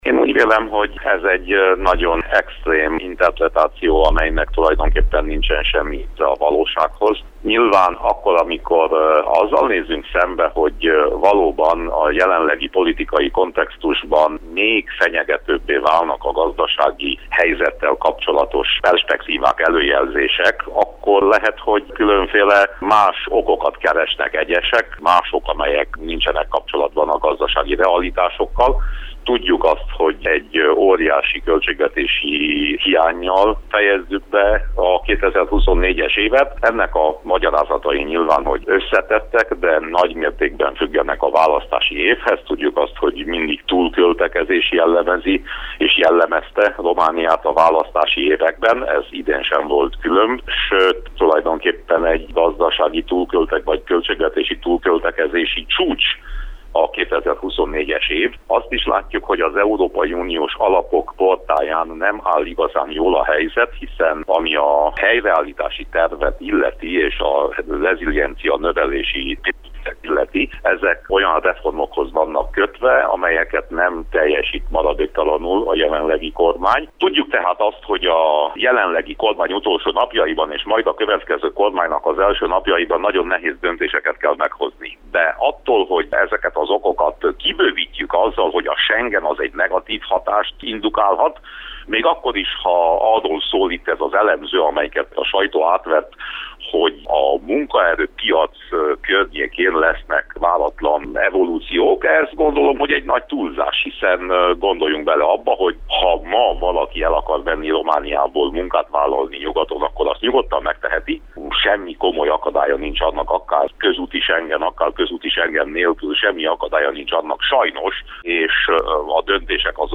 Mennyire valószínűsíthető a schengeni csatlakozással együttjáró gazdasági válság? – kérdeztük Winkler Gyula európai uniós képviselőt, közgazdászt.
interjúja